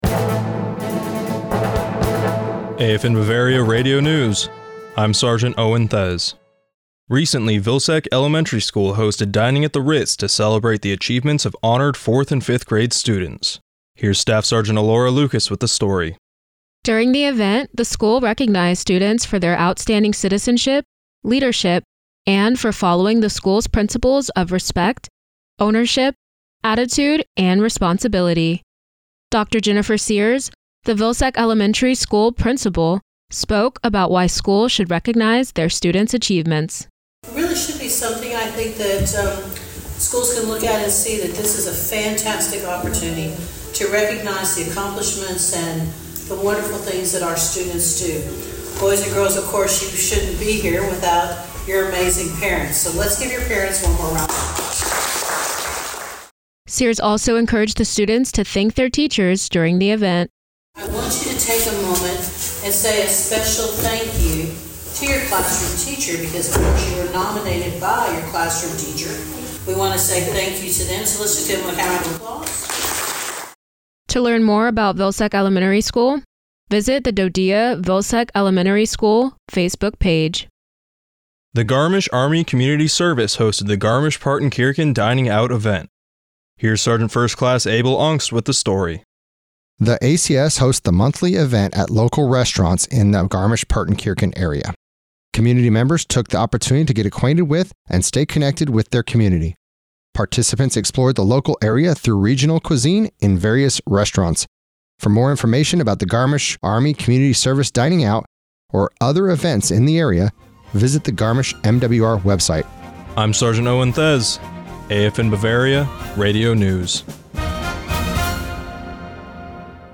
AFN Bavaria Radio News January 26, 2024